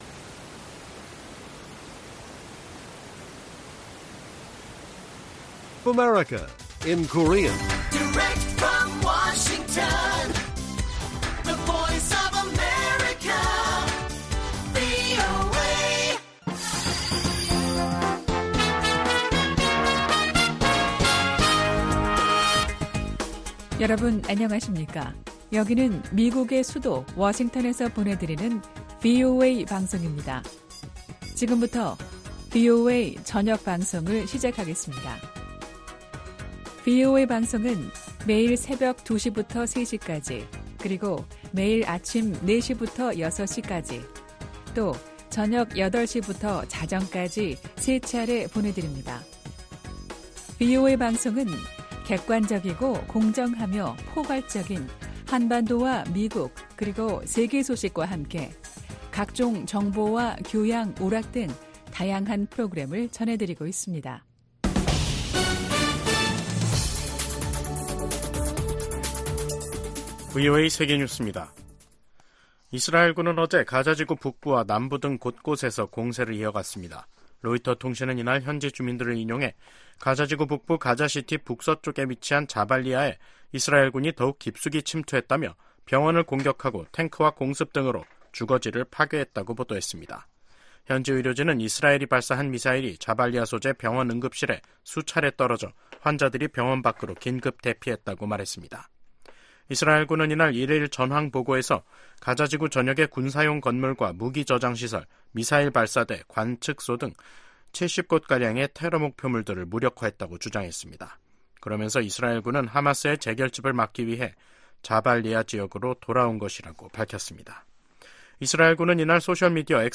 VOA 한국어 간판 뉴스 프로그램 '뉴스 투데이', 2024년 5월 22일 1부 방송입니다. 토니 블링컨 미국 국무장관이 러시아에 대한 북한의 직접 무기 지원을 비판했습니다. 미국 국방부는 미국과 동맹들이 한반도 긴장을 고조시킨다는 러시아의 주장을 일축하고, 미한일 협력이 역내 평화와 안보, 안정을 가져왔다고 강조했습니다. 미국과 영국, 호주에 이어 캐나다 정부도 북한과 러시아의 무기 거래를 겨냥한 제재를 단행했습니다.